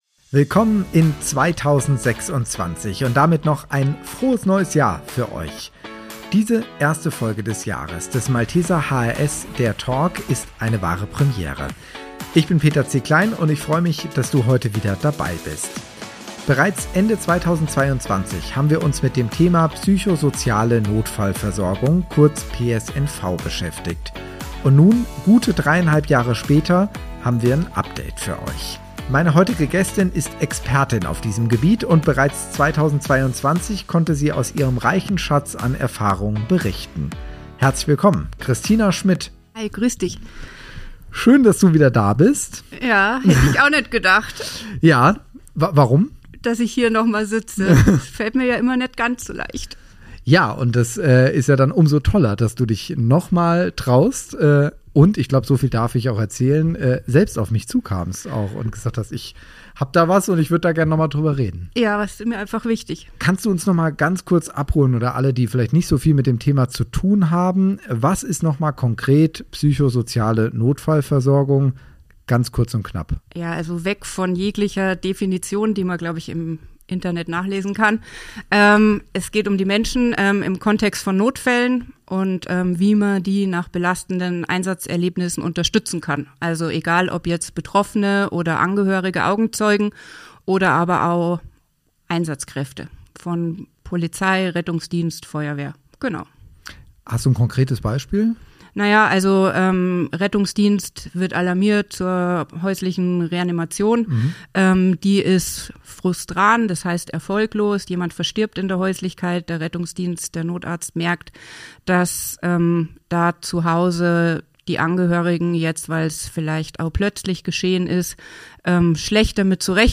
Psychosoziale Notfallversorgung, das Update 2026 ~ Malteser HRS, der Talk Podcast